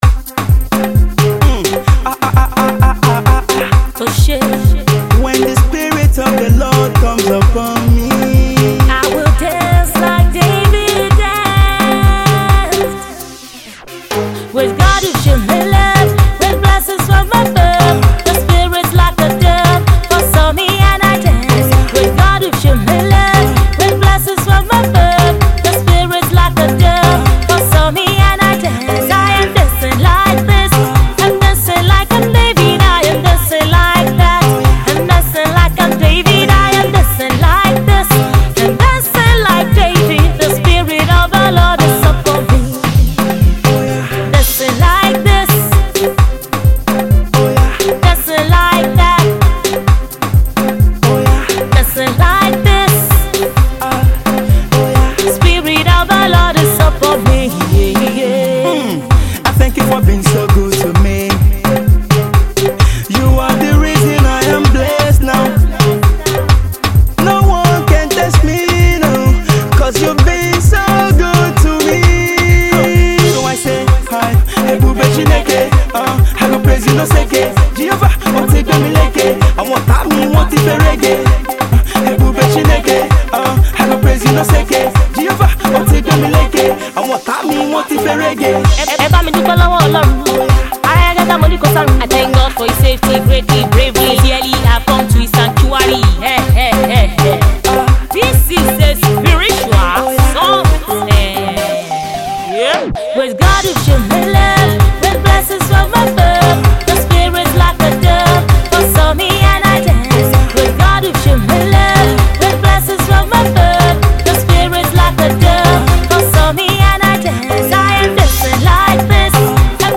upbeat gospel hip tune